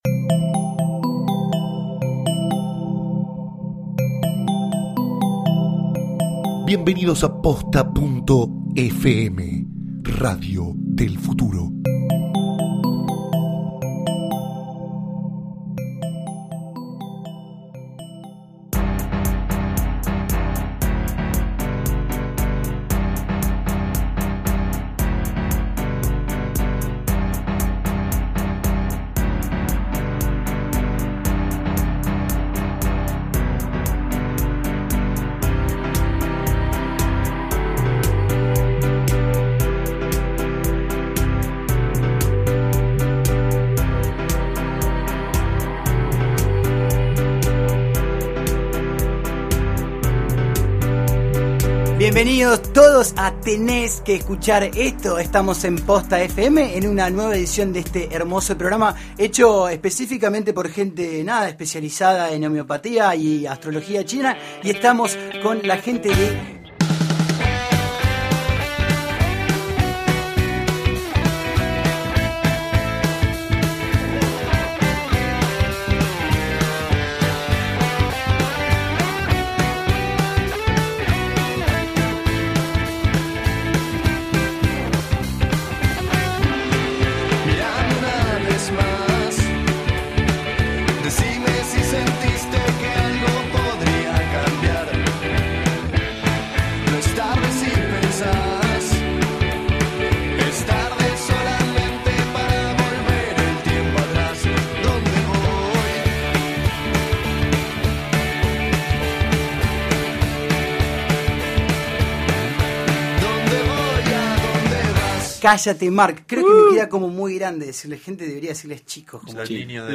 recibe en el estudio a los jovencísimos y geniales Callate Mark, la nueva ola del new wave. Empezaron a los 13 y hoy nos cuentan cómo es tener una banda a los 20, qué escuchan, cómo fueron mutando sus gustos y mucho más.